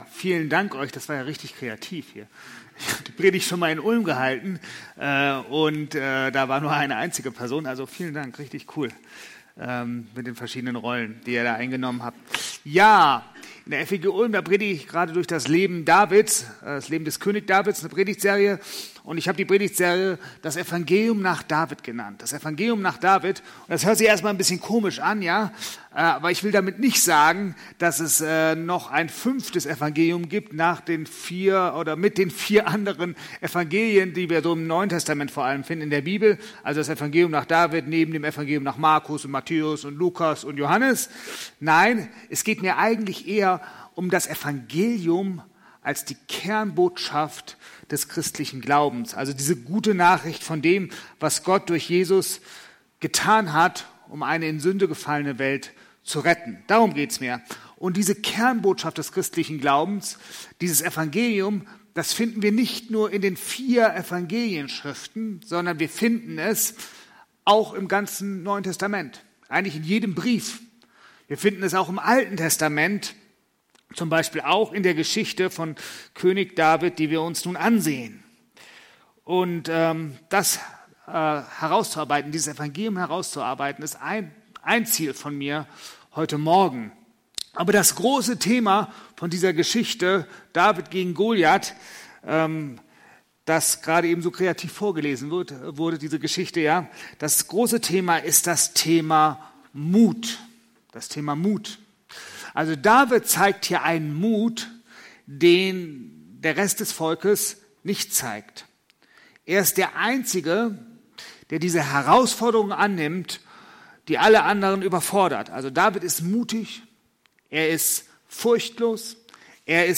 Predigt vom 18.10.2020